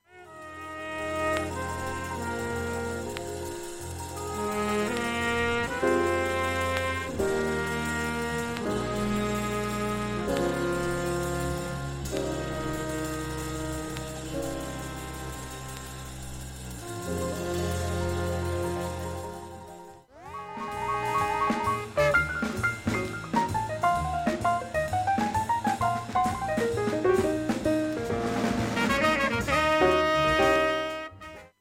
盤面きれいで音質良好全曲試聴済み
A-1始めにかすかなプツが９回出ます。
終盤にかすかなプツが４回出ます。